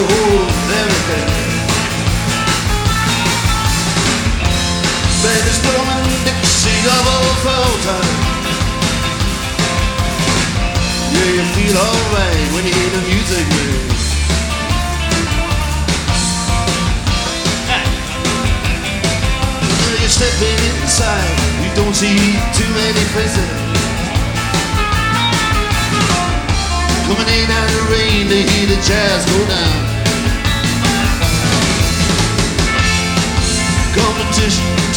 Rock Pop
Жанр: Поп музыка / Рок